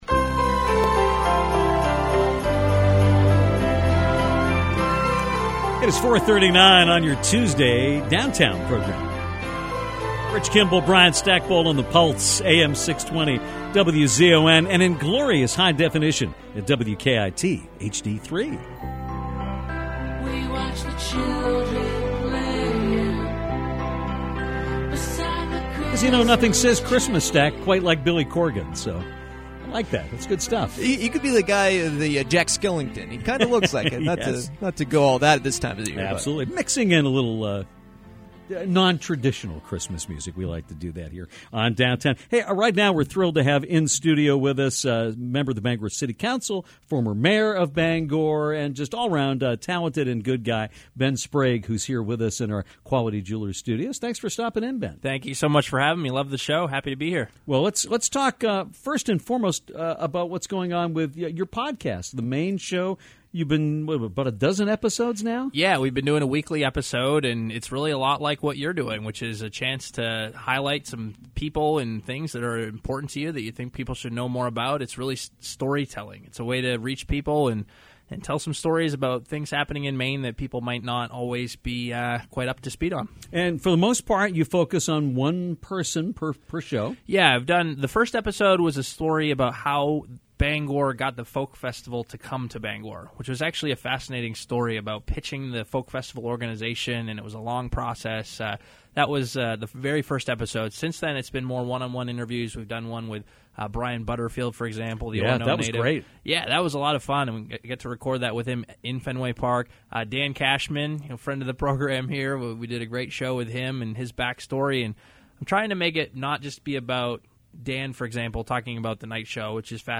in the Seasons Restaurant Studios to talk about his tenure in the chamber and also to discuss his new podcast “The Maine Show”. Sprague spoke about what got him into podcasting in the first place and why he tried to continue the Maine ties into the medium. He talked about some of the issues that he has faced as a member of the Bangor City Council and gave some insight about his time working with the Boston Red Sox.